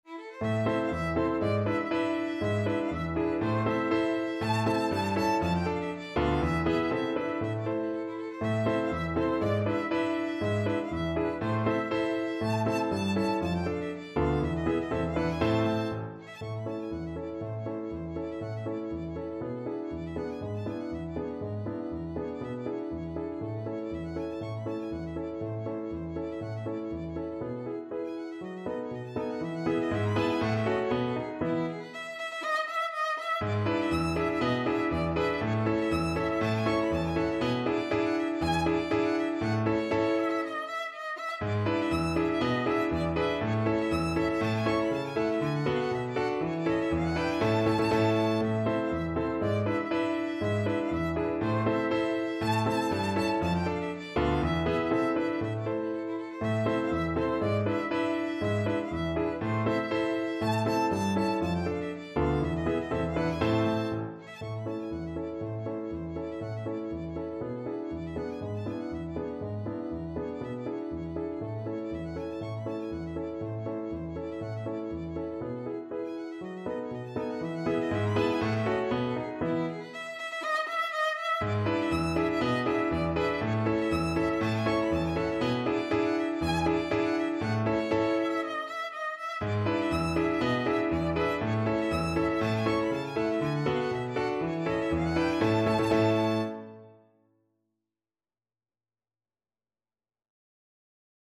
2/4 (View more 2/4 Music)
Classical (View more Classical Violin Music)